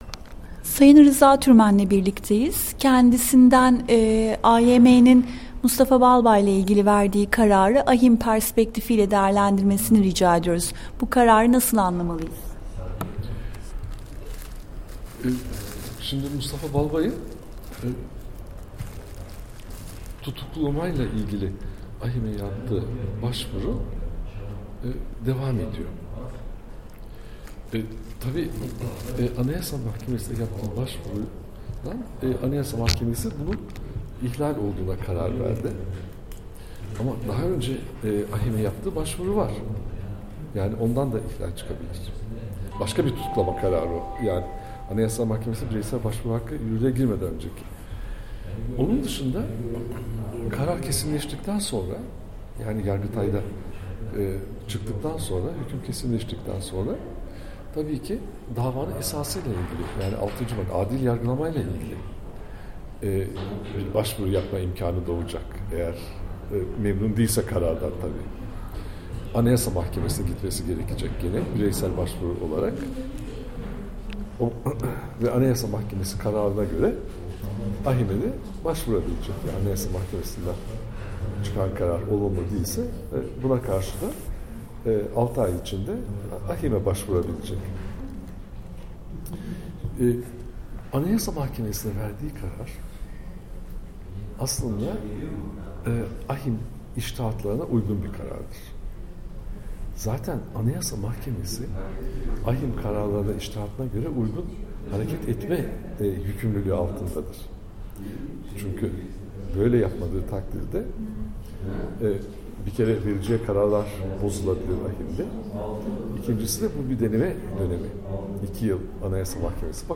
Rıza Türmen ile Söyleşi